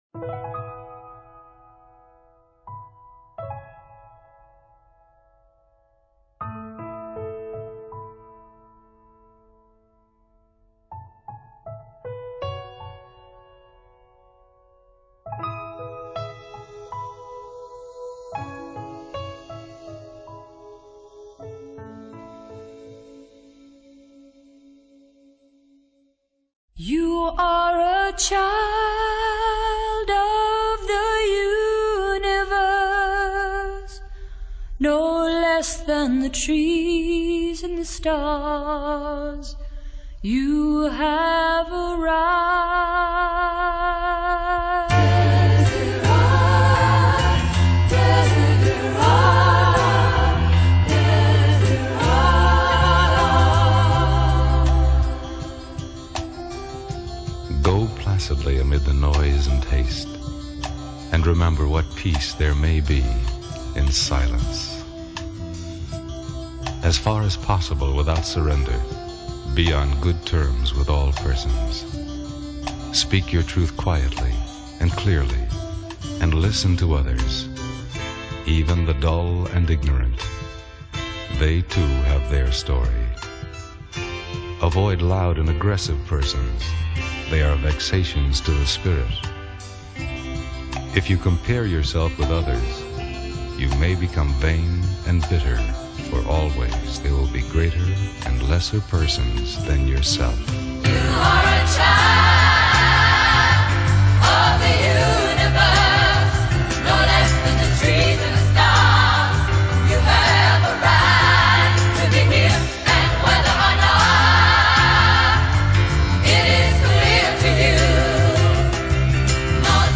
. spoken songs